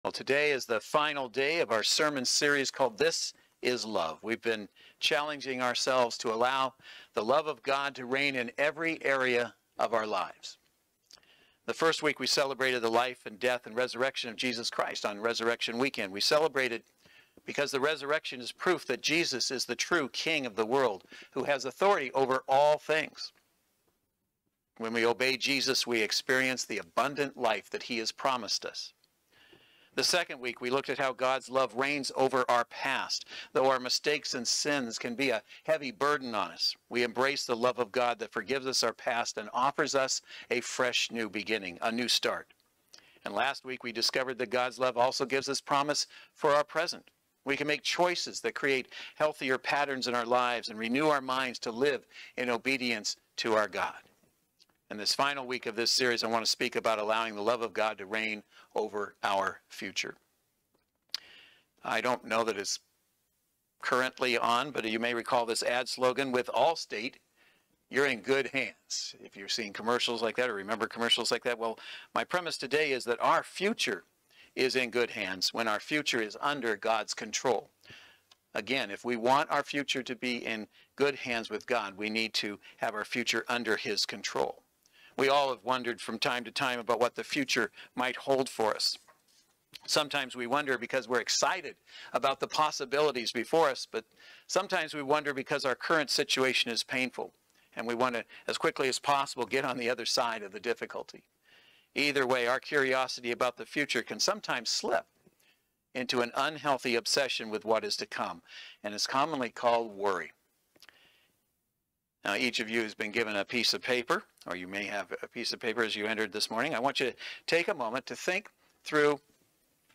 This is Love Service Type: Saturday Worship Service Are you worried about what your future holds?